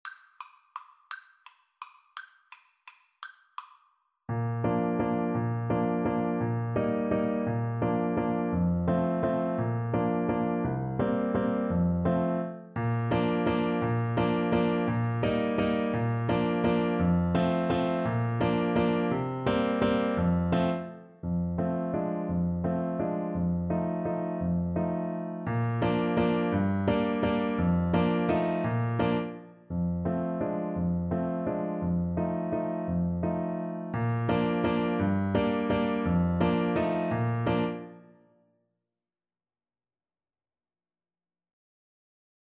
3/4 (View more 3/4 Music)
Classical (View more Classical Trombone Music)